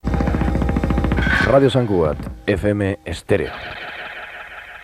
Indicatiu curt de l'emissora